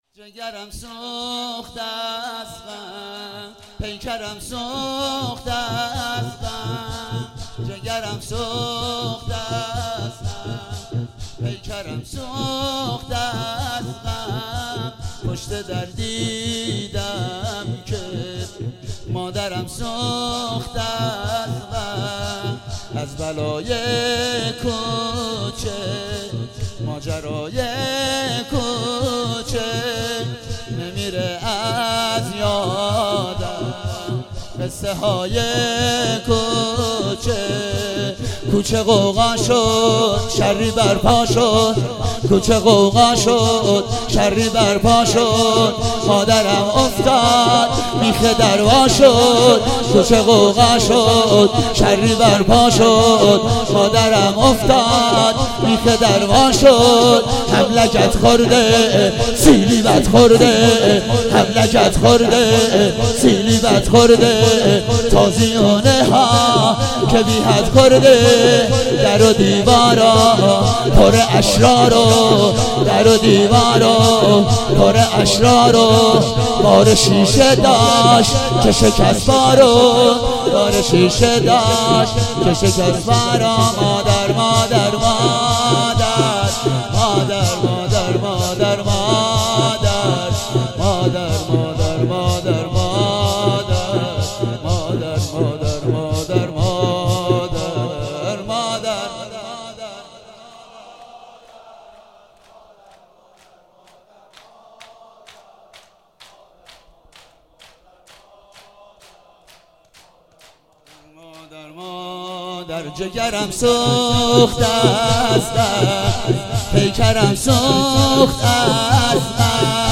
هفتگی 26 مهر 97 - زمینه - جگرم سوخت از غم